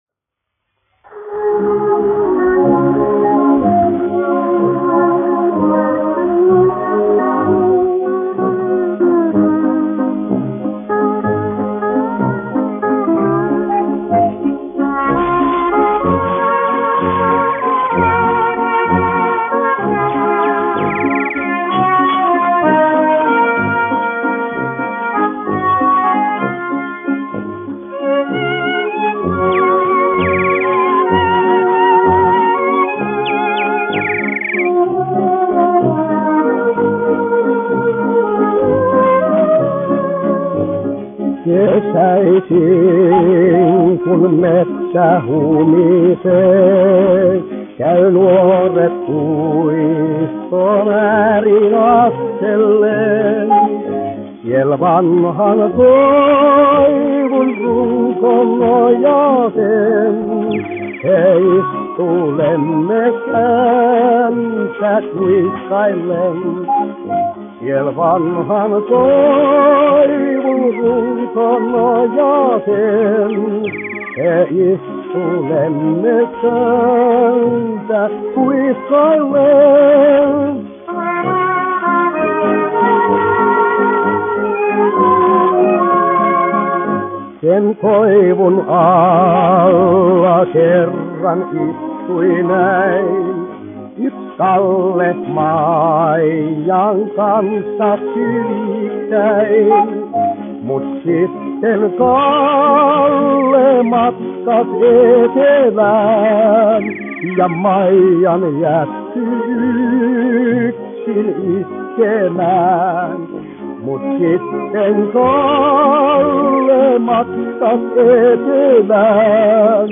1 skpl. : analogs, 78 apgr/min, mono ; 25 cm
Valši
Populārā mūzika
Latvijas vēsturiskie šellaka skaņuplašu ieraksti (Kolekcija)